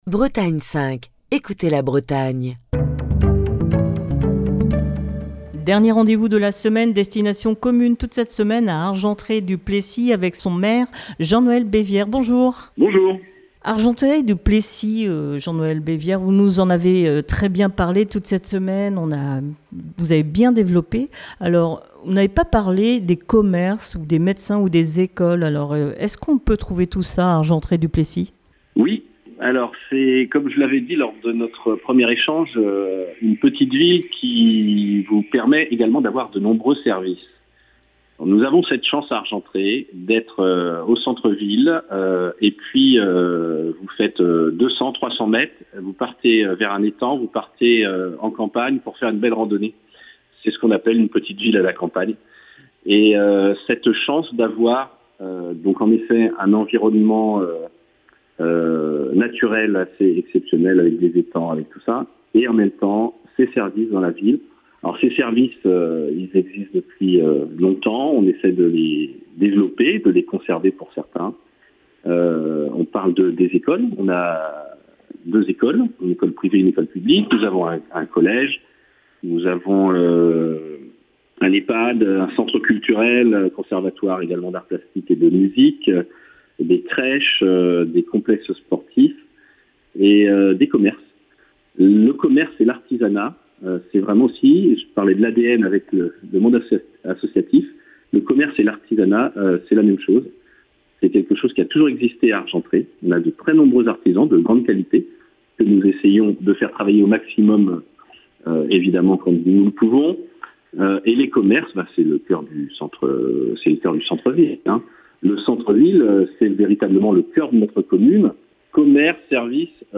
Émission du 20 septembre 2024. Destination commune passait cette semaine à Argentré-du-Plessis en Ille-et-Vilaine.